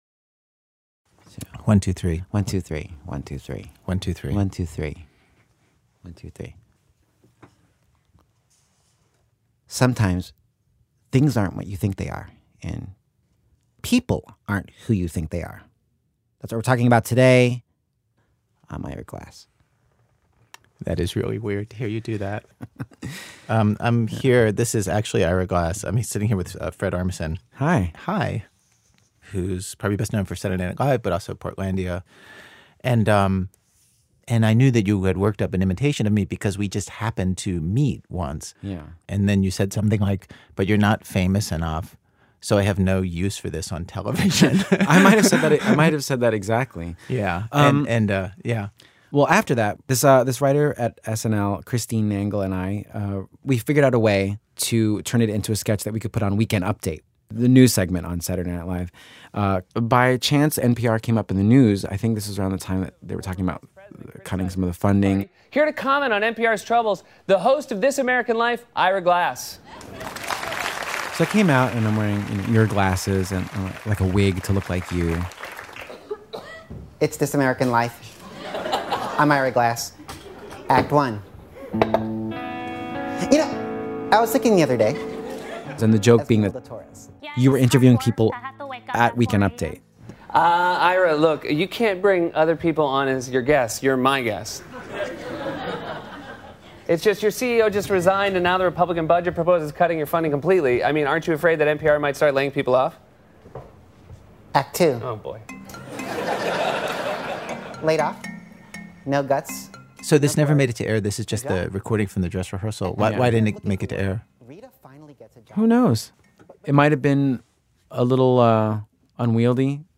Fred Armisen co-hosts with Ira Glass.